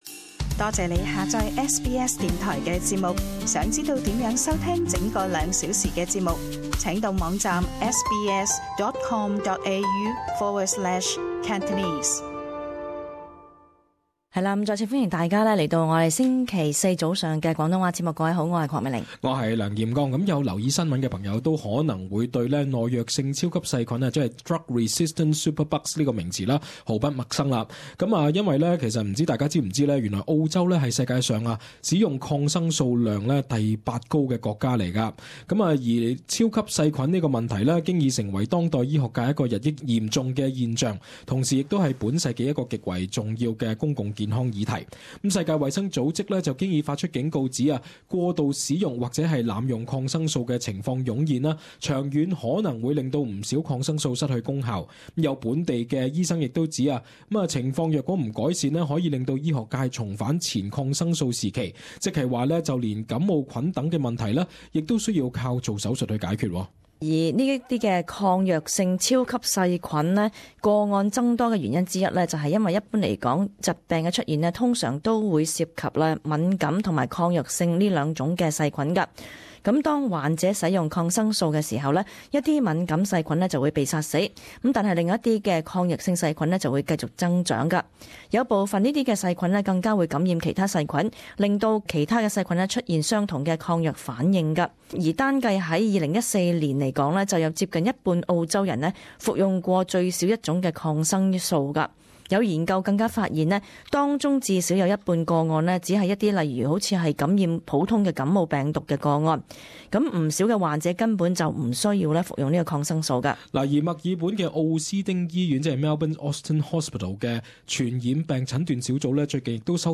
【時事報導】「超級細菌」新症湧現澳洲惹關注